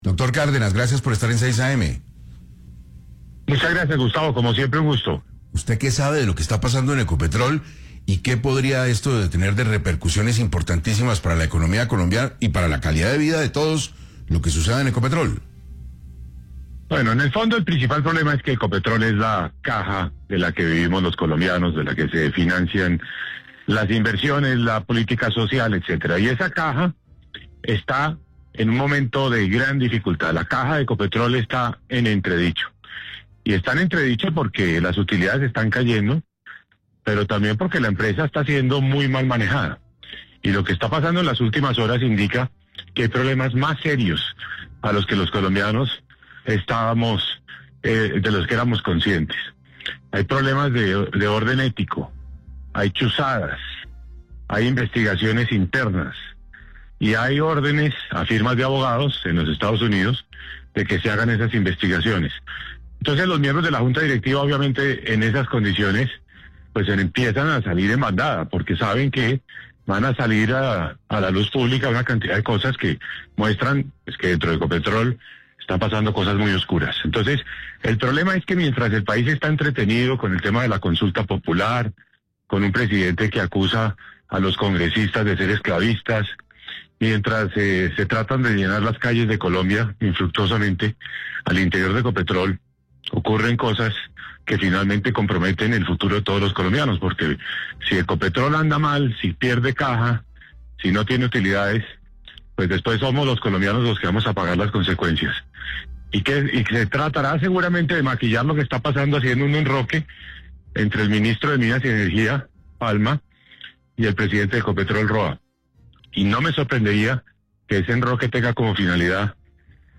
En entrevista con 6AM de Caracol Radio, el exministro de Hacienda Mauricio Cárdenas encendió las alarmas sobre la grave situación que enfrenta Ecopetrol, empresa clave para las finanzas del Estado colombiano.